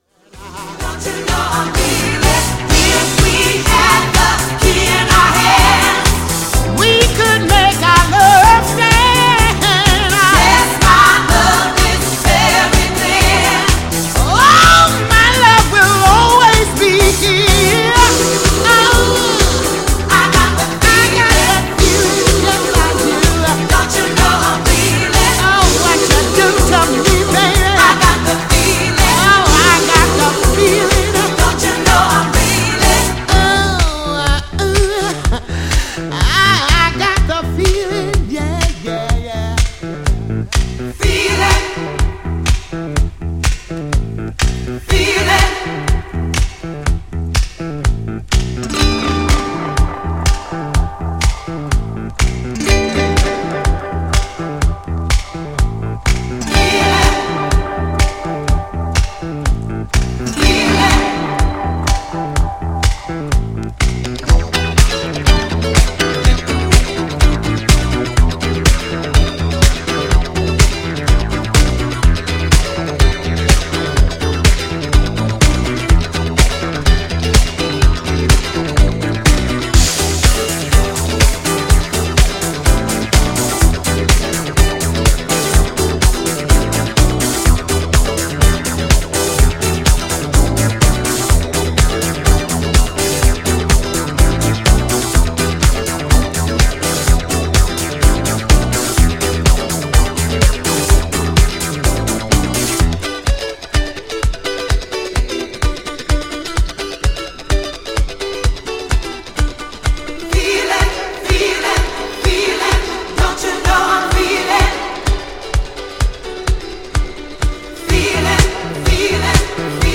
一生聴くことが出来る極上ソウルとはコレです。
GENRE Dance Classic
BPM 101〜105BPM
# アフターアワーズ # ソウル # ハートウォーム # 熱いボーカル